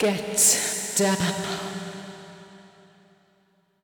House / Voice / VOICEGRL097_HOUSE_125_A_SC2.wav